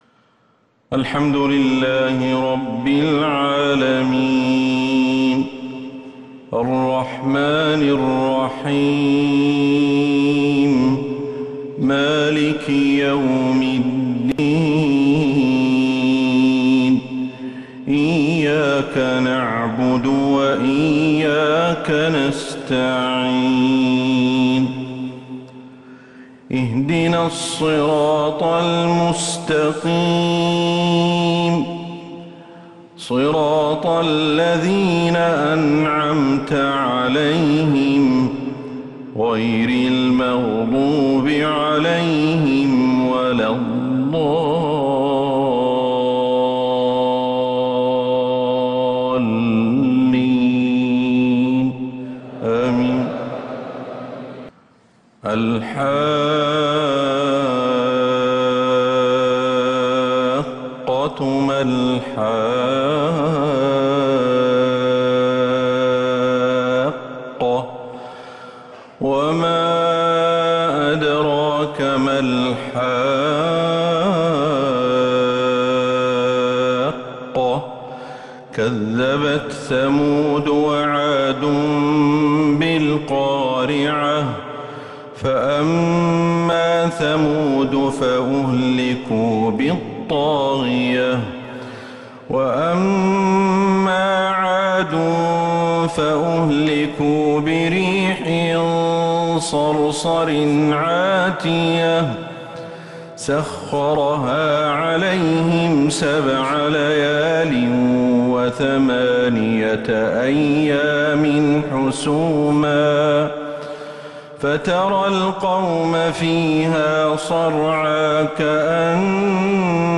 عشائية خاشعة محبرهـ لما تيسر من سورة {الحاقة} الأربعاء 19 محرم 1444هـ > 1444هـ > الفروض - تلاوات الشيخ أحمد الحذيفي